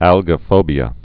(ălgə-fōbē-ə)